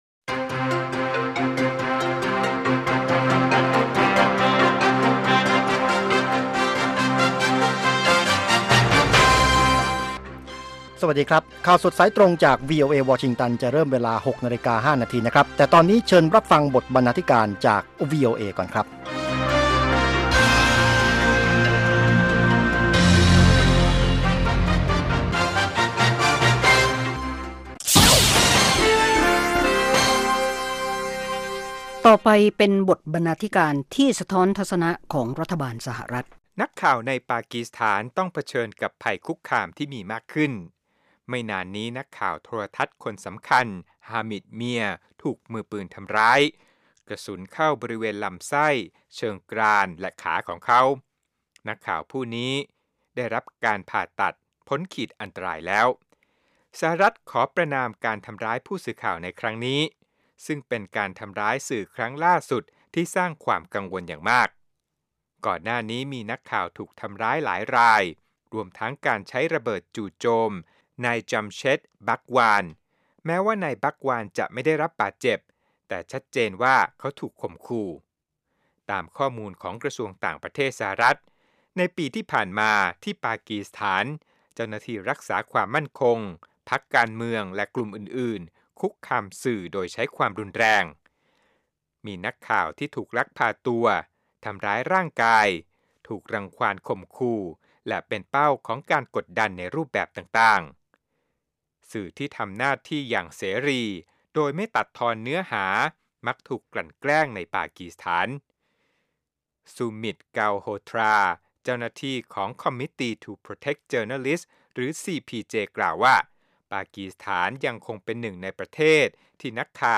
ข่าวสดสายตรงจากวีโอเอ ภาคภาษาไทย 6:00 – 6:30 น.